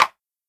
DrRim15.wav